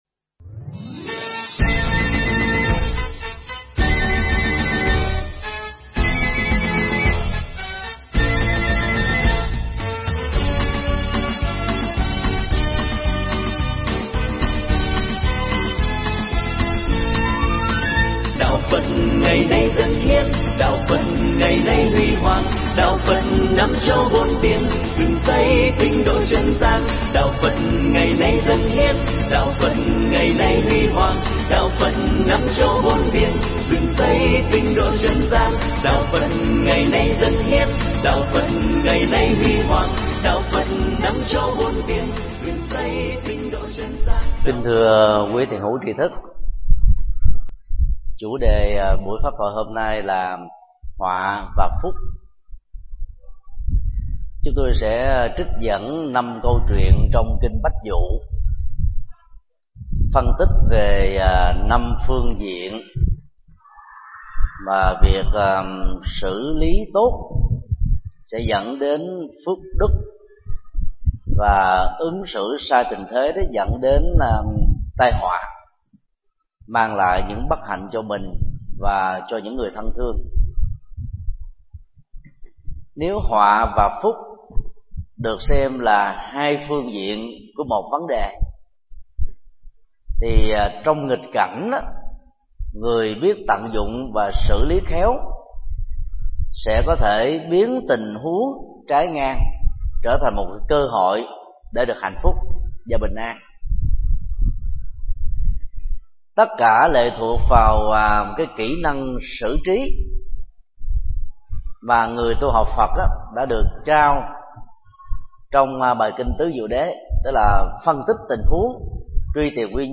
Nghe mp3 pháp thoại Kinh Bách Dụ 14 (Bài 62 – 66): Họa và phúc do thầy Thích Nhật Từ giảng tại chùa Xá Lợi, ngày 20 tháng 11 năm 2011